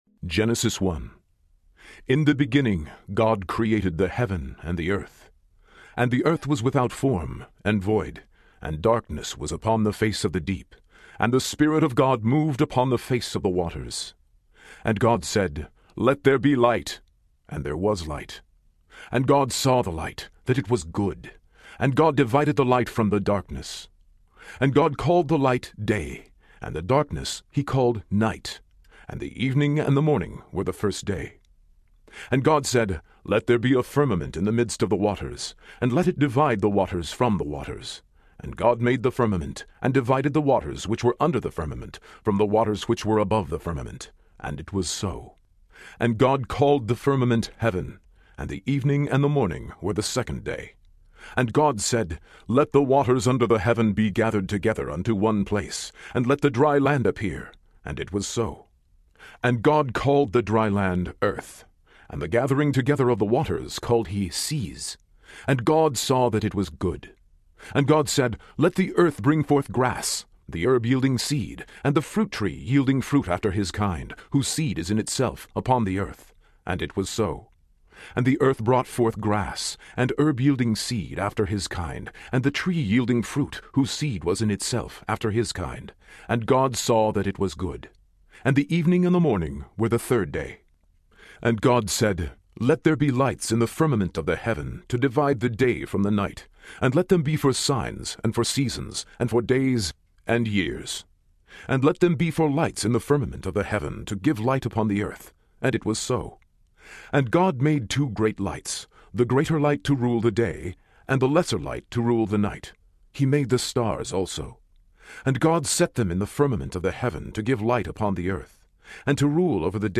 These two lush baritone vocals are the perfect complement to one another. Mr. Jones’ skilled and resonating reading has been hailed as one of the greatest New Testament recordings of all time.
75.5 Hrs. – Unabridged